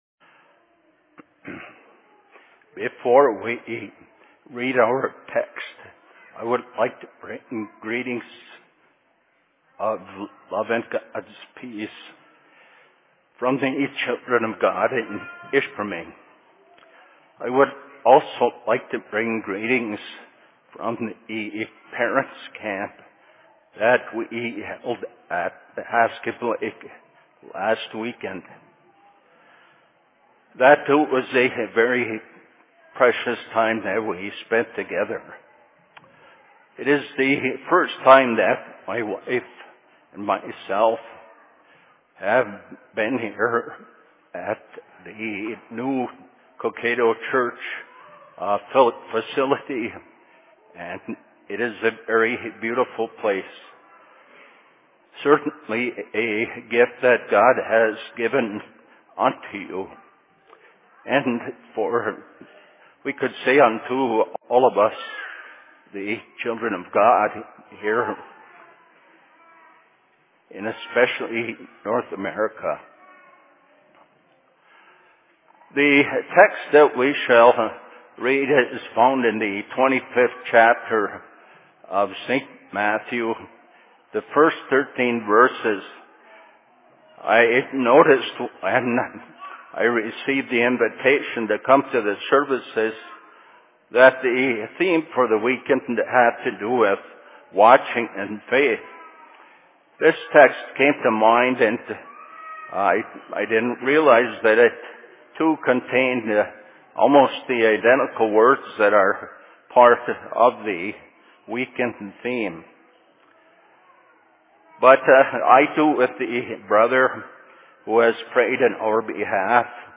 Fall Services/Sermon in Cokato 04.10.2013